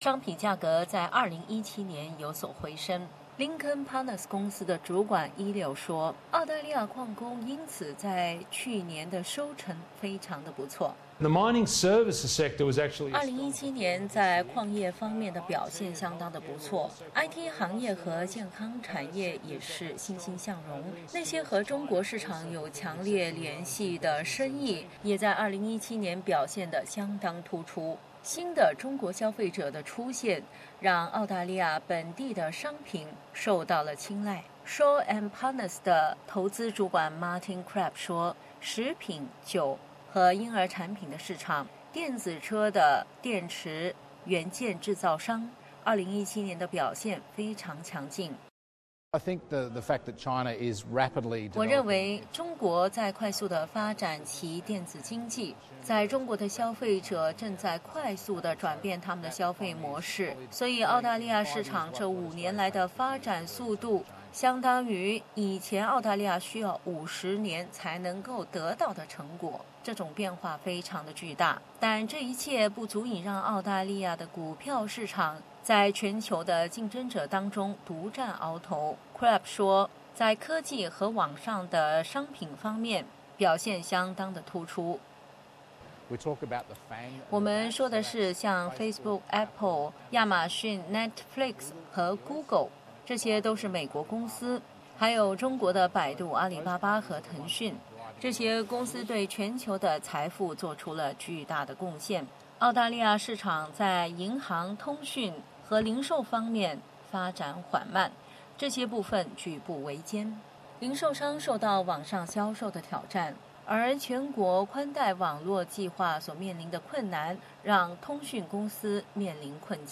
04:24 Source: AAP SBS 普通话电台 View Podcast Series Follow and Subscribe Apple Podcasts YouTube Spotify Download (2.02MB) Download the SBS Audio app Available on iOS and Android 全球股票市场飘高，澳大利亚退休公积金也将享受最高的回报。